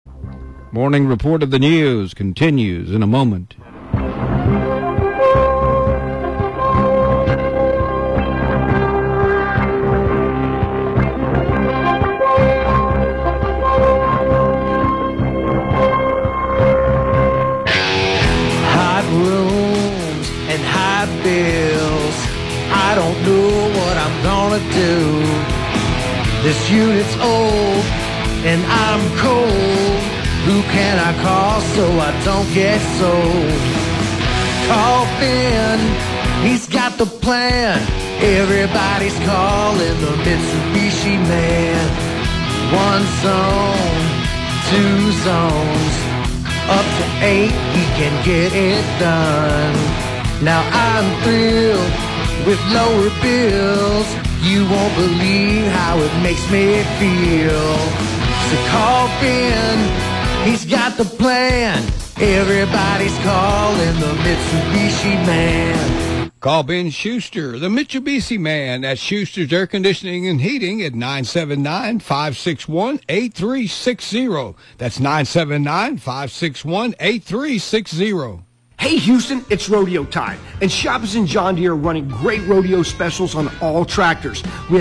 I made a jingle! (Comes in after news bumper)
Made with Logic Pro on iPad and a Quad Cortex for Amps n effects. Rock Drummer on the beat.